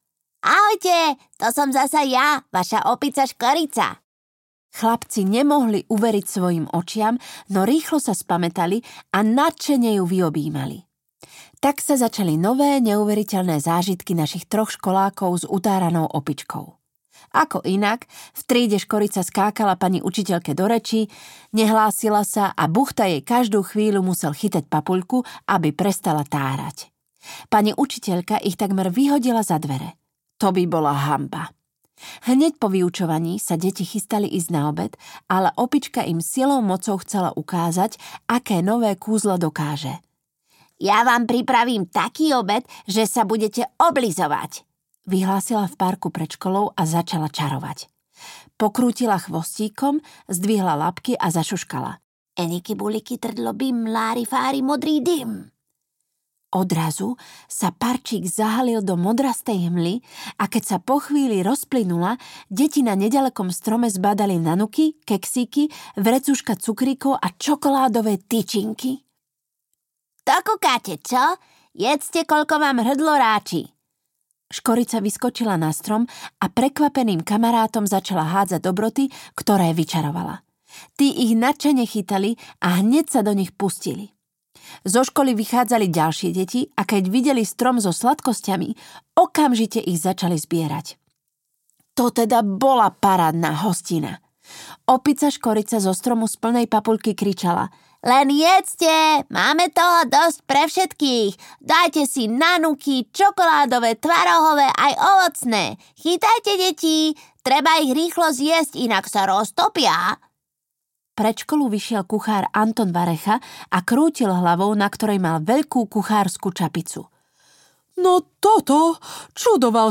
Vypočujte si ukážku audioknihy